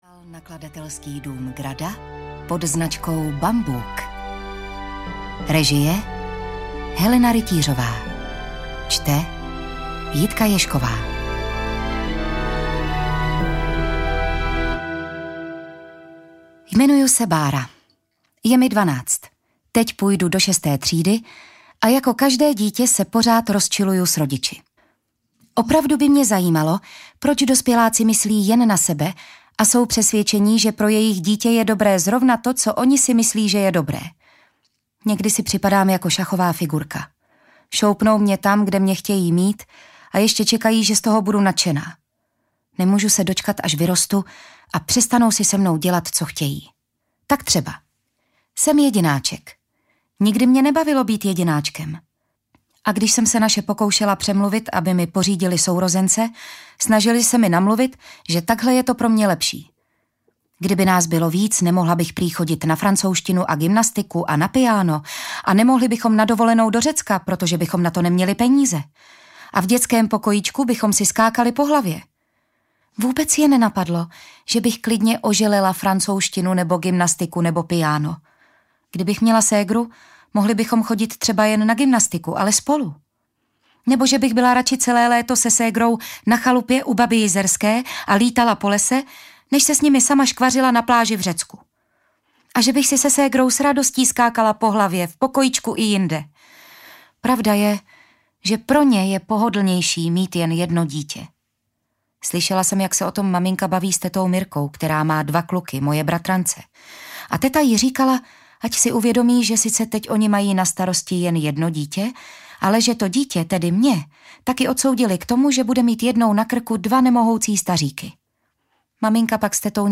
Ukázka z knihy
terezinske-ghetto-audiokniha